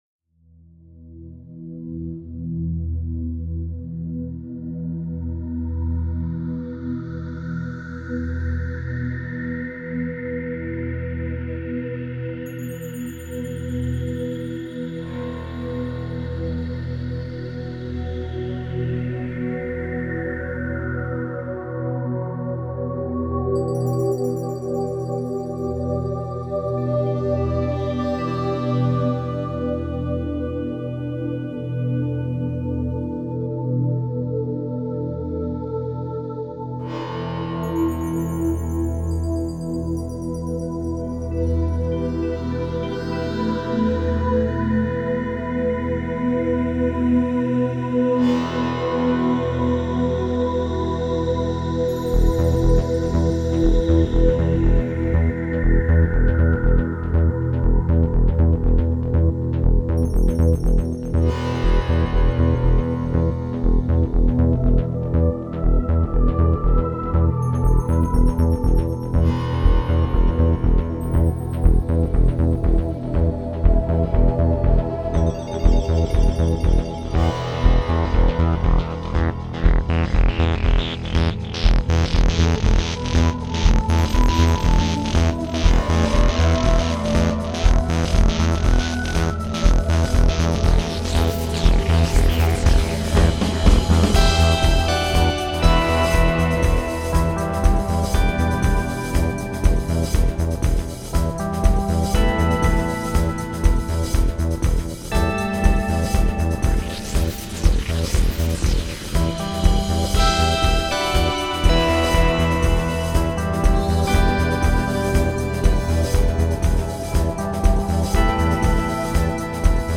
enthusiasts and fanatics in the electronic music genre.